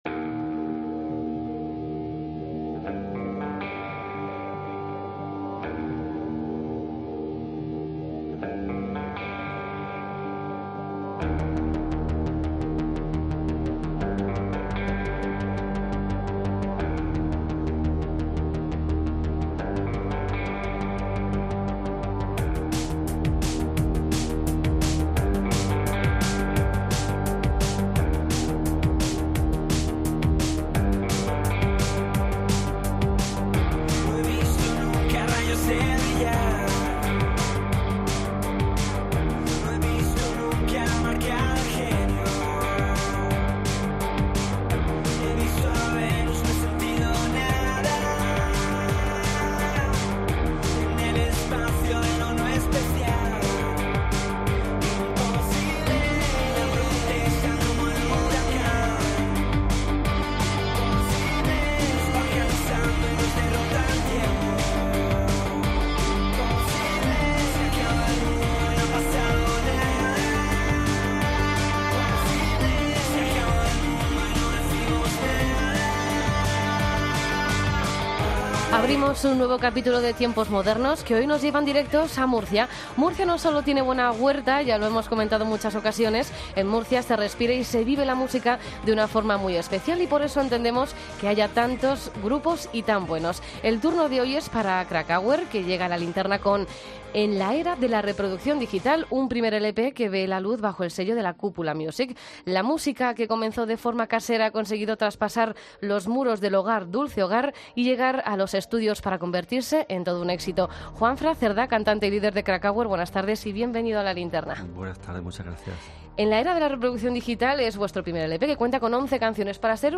Entrevista a Kracauer en La Linterna